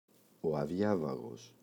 αδιάβαγος [aꞋðʝavaγos]